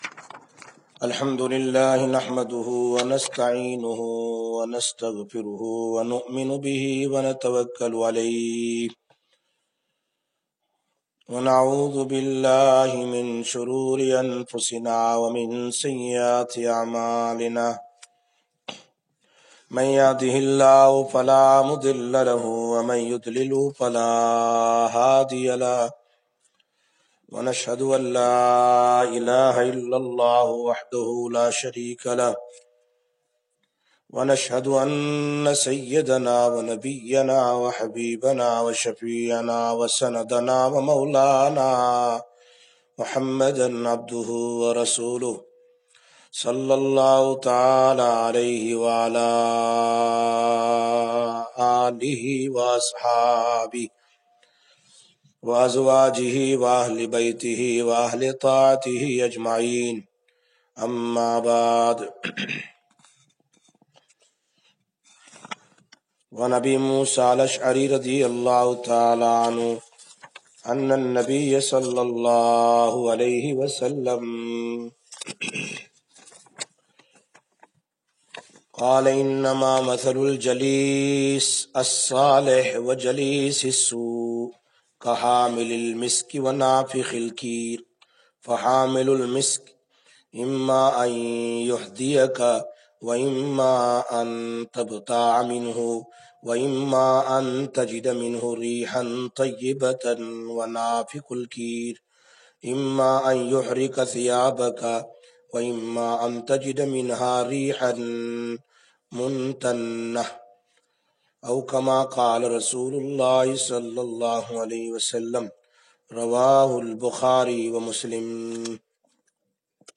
26/09/18 Sisters Bayan, Masjid Quba